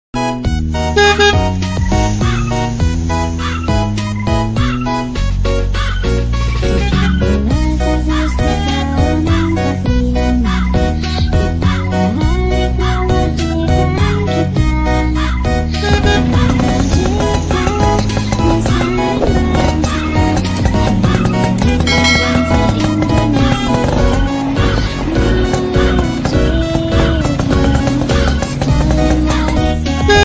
pawai karnaval truk sound system